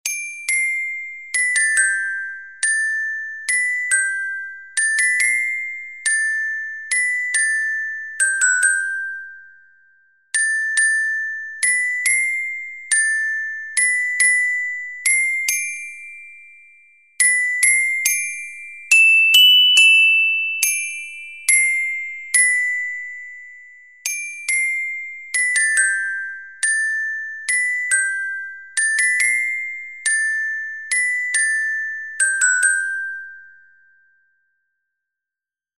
The second, Deck the Hall, is a 16th-century Welsh melody, whose lyrics were written by the Scottish musician Thomas Oliphant in 1862.
Exercise 2: 4/4 time signature.